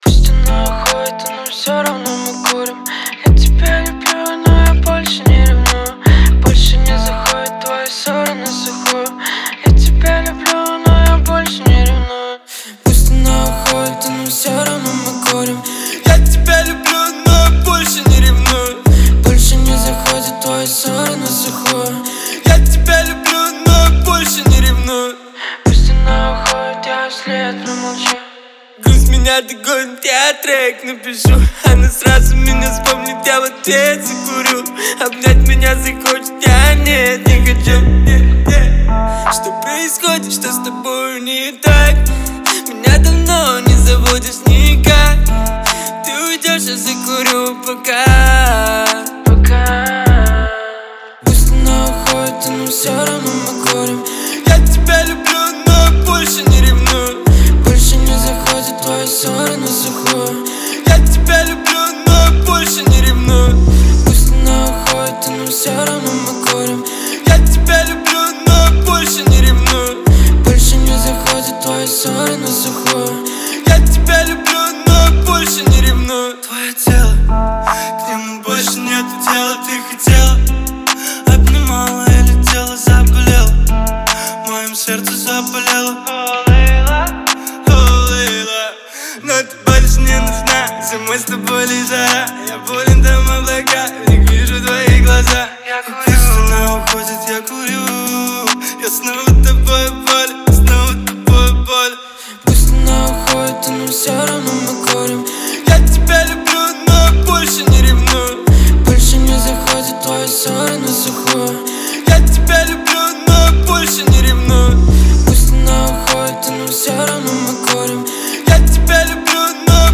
нежный вокал
атмосферные мелодии